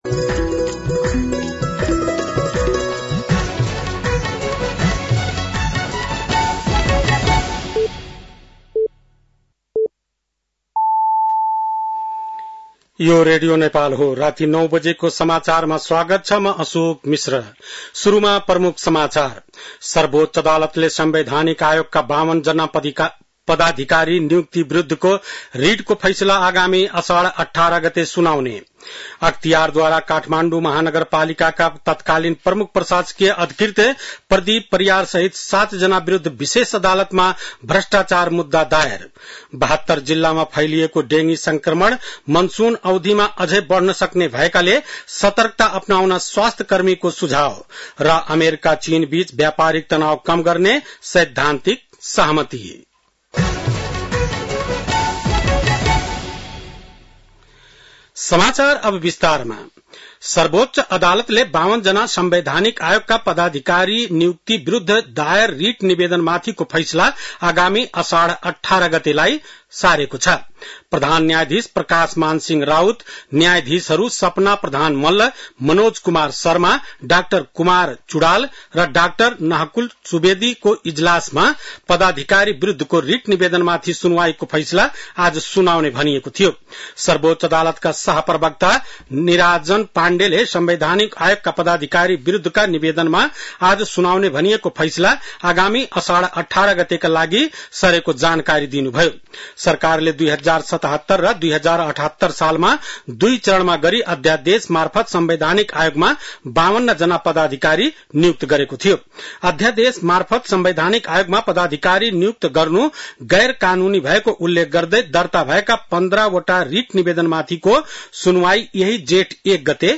बेलुकी ९ बजेको नेपाली समाचार : २८ जेठ , २०८२
9-PM-Nepali-NEWS-1-2.mp3